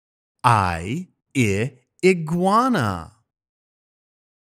音声を聴いて、このゲームの中で使われている単語をフォニックスの読み方で発音してみよう！